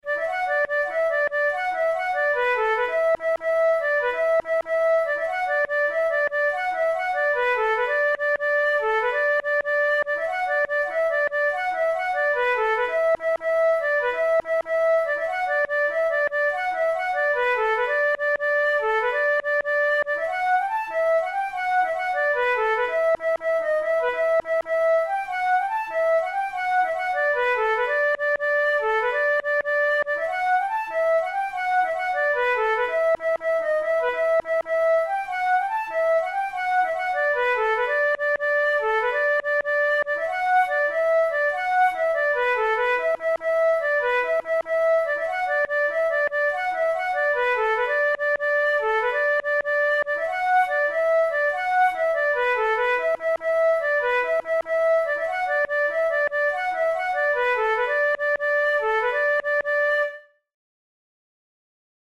Traditional Irish jig
This lightly ornamented three-part setting